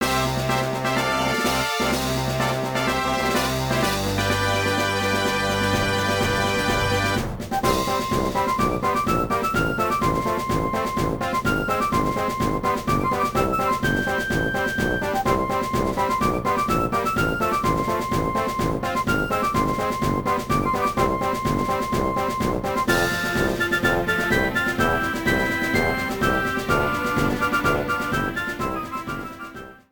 The ending theme
Ripped from game data, then trimmed in Audacity